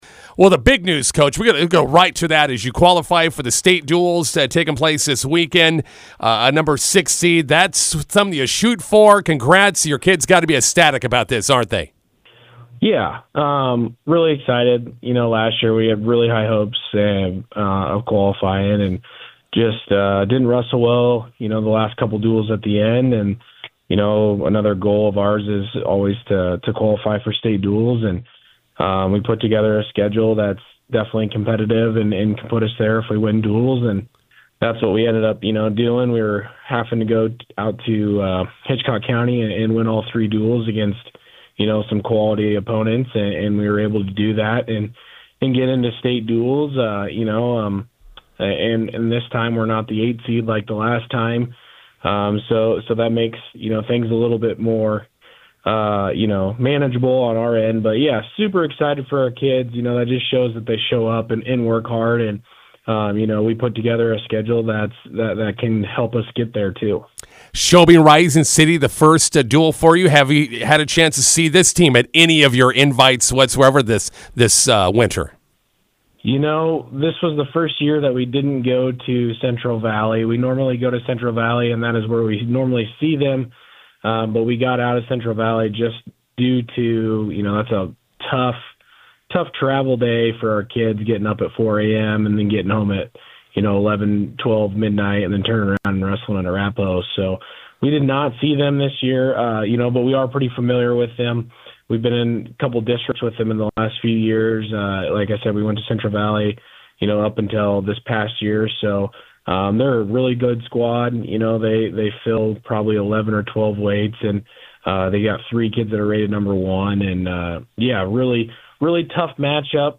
INTERVIEW: Cambridge wrestlers qualify for the Class D state duals this weekend.